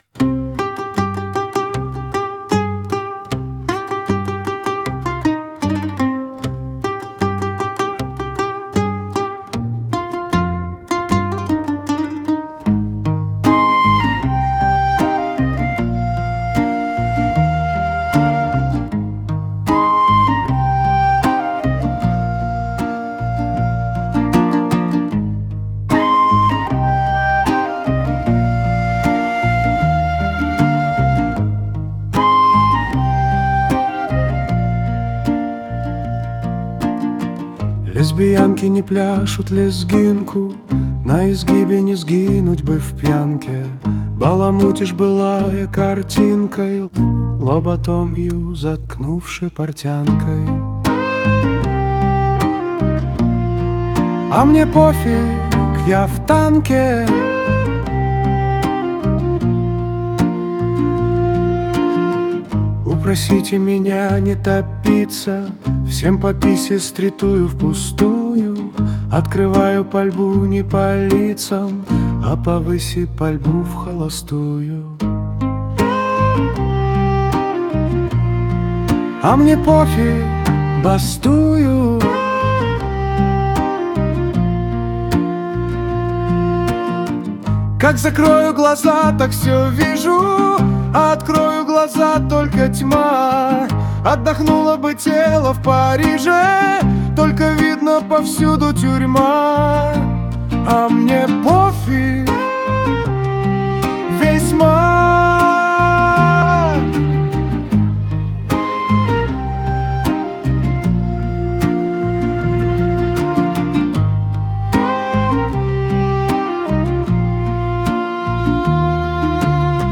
• Жанр: Комедия